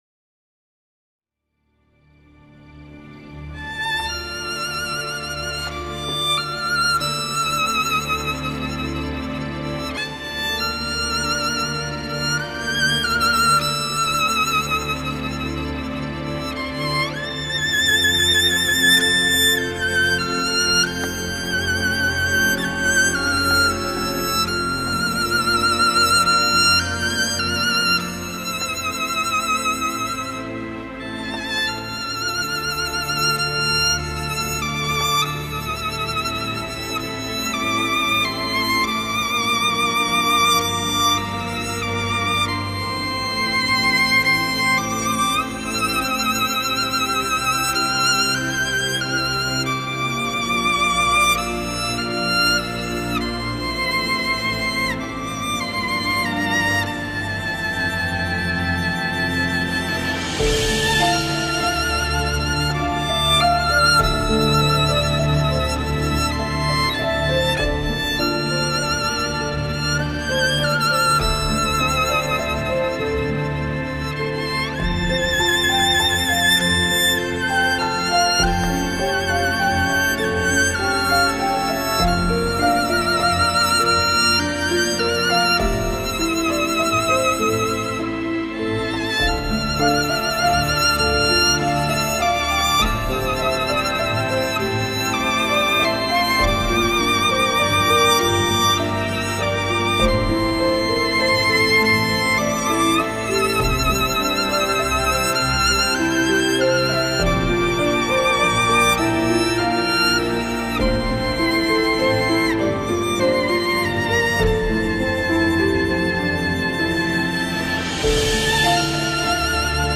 tema dizi müziği